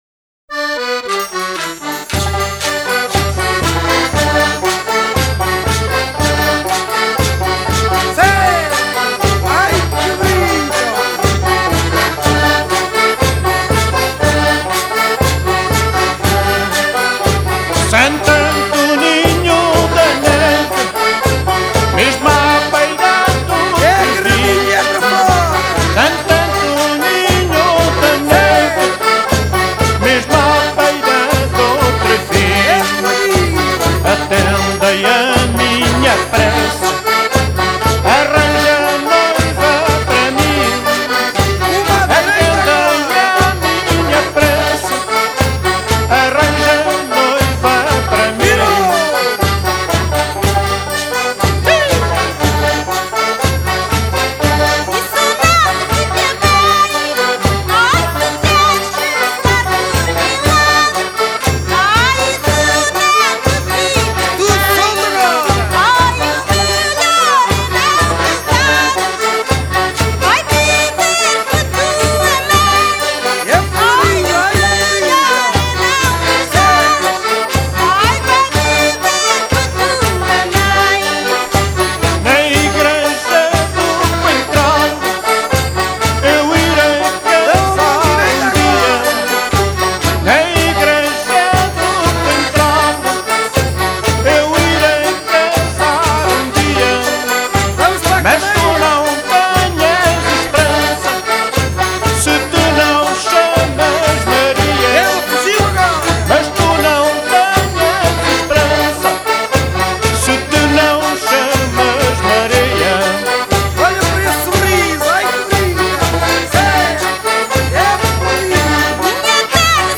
Ficheiros áudio do Rancho Folclórico Neveiros do Coentral